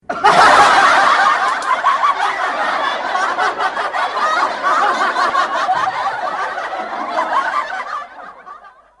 Звуки закадрового смеха